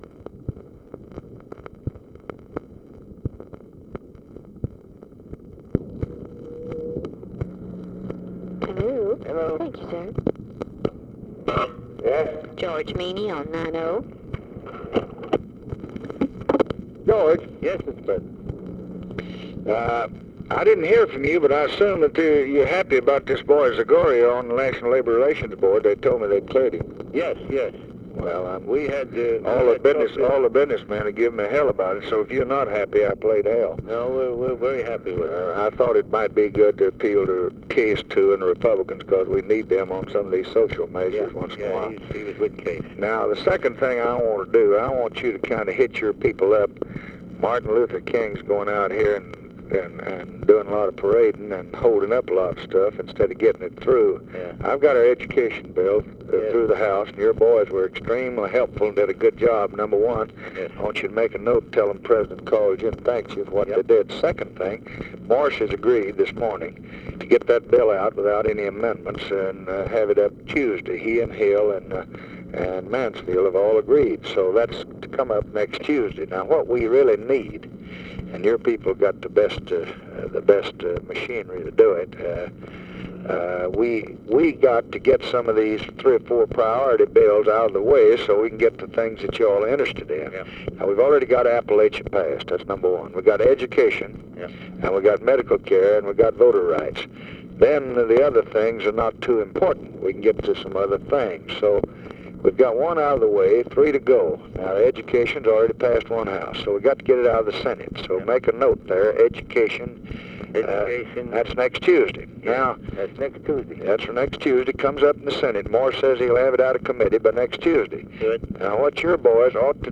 Conversation with GEORGE MEANY, March 29, 1965
Secret White House Tapes